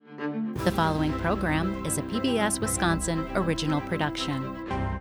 PBS WI- ORIGINAL PROD HEADER 05_Female_REV 21.wav